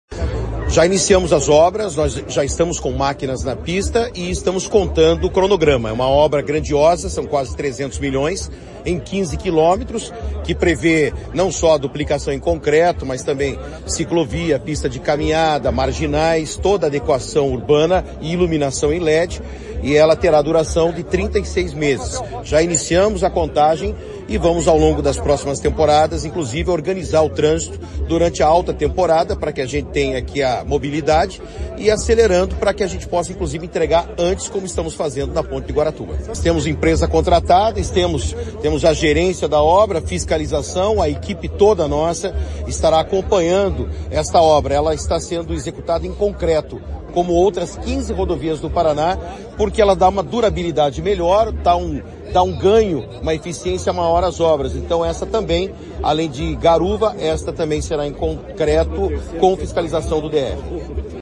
Sonora do secretário de Infraestrutura e Logística, Sandro Alex, sobre início da duplicação em concreto da PR-412 entre Matinhos e Pontal do Paraná